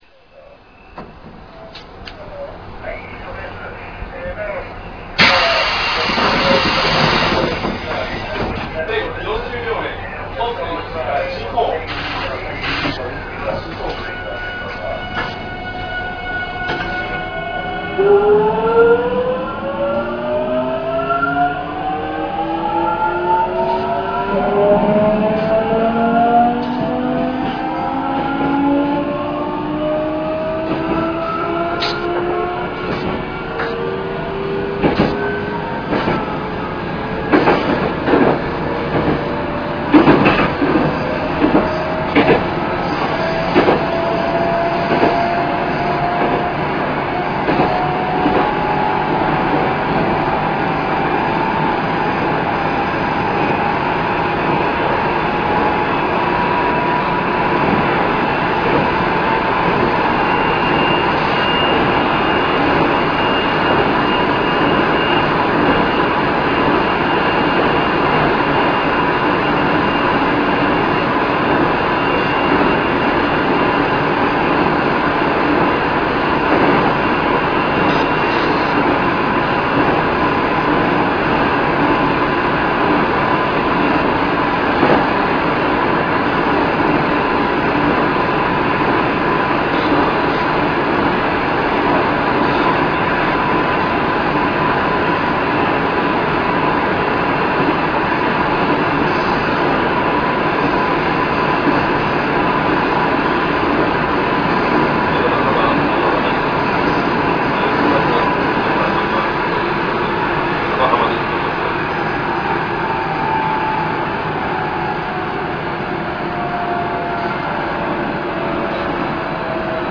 F13編成走行音(1)[373-13c.ra/336KB]
制御方式：VVVFインバータ制御(東芝GTO・個別制御)
主電動機：C-MT66(185kW)